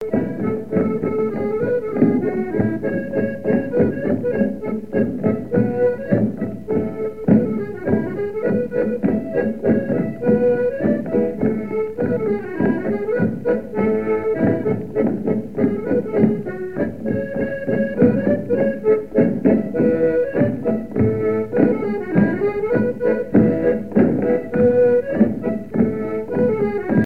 Chants brefs - A danser
danse : scottich sept pas
airs de danses issus de groupes folkloriques locaux
Pièce musicale inédite